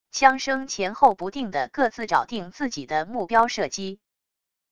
枪声前后不定的各自找定自己的目标射击wav音频